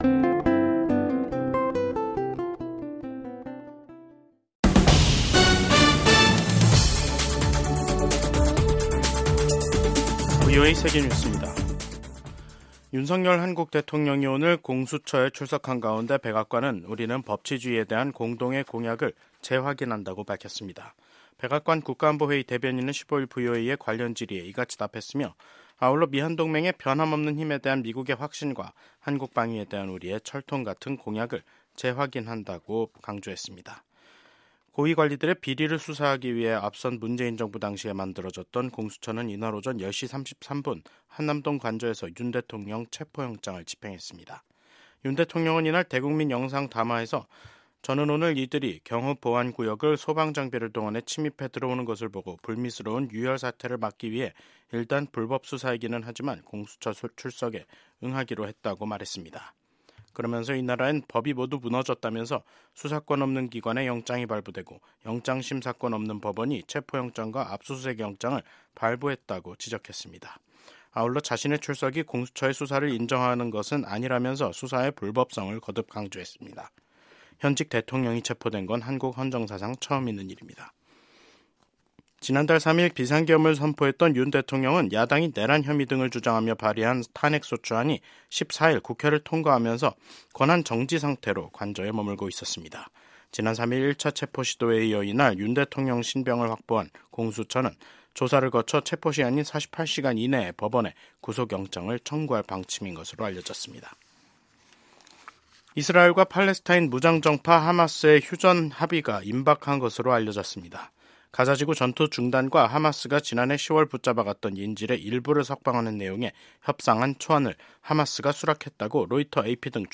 VOA 한국어 간판 뉴스 프로그램 '뉴스 투데이', 2025년 1월 15일 2부 방송입니다. 비상계엄 선포로 내란죄 혐의를 받고 있는 윤석열 대통령이 현직 대통령으론 한국 헌정사상 처음 사법기관에 체포됐습니다.